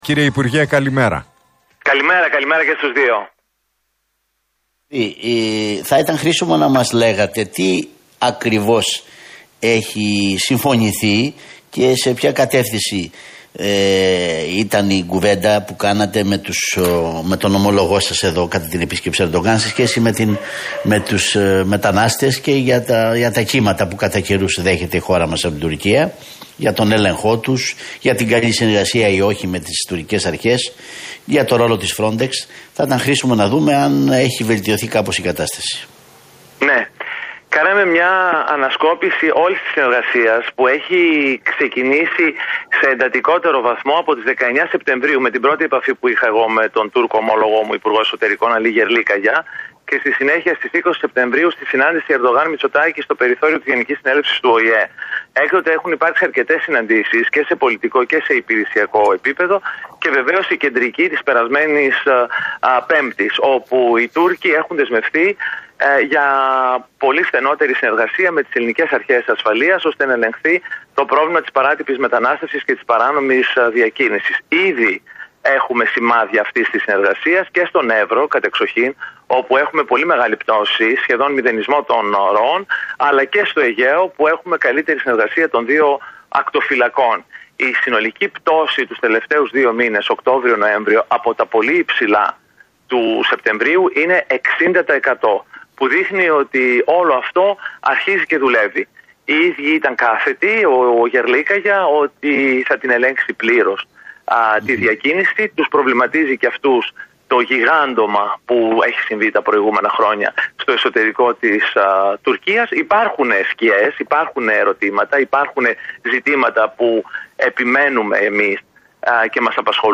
Δείχνει ότι όλο αυτό αρχίζει να δουλεύει» δήλωσε στην εκπομπή του Νίκου Χατζηνικολάου με τον Αντώνη Δελλατόλα στον Realfm 97,8 ο υπουργός Μετανάστευσης και Ασύλου, Δημήτρης Καιρίδης.